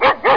1 channel
bark4.mp3